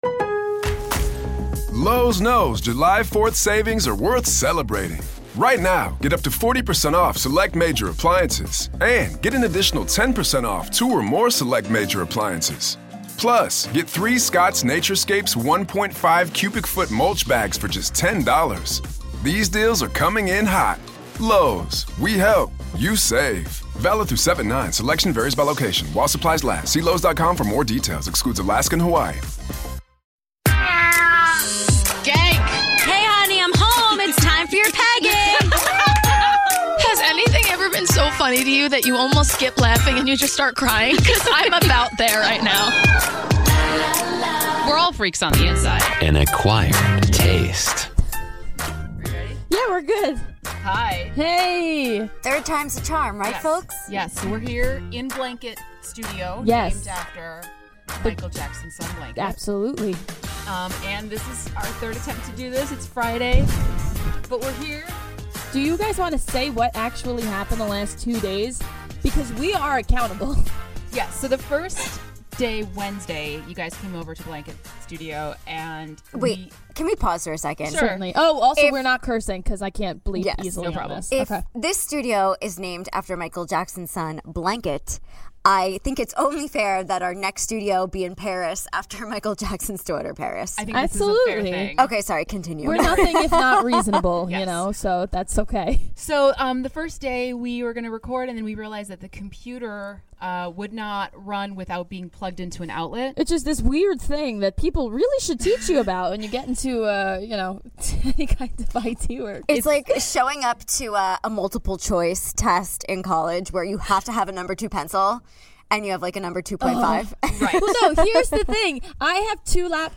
Live from Blanket Studio!